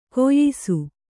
♪ koyyisu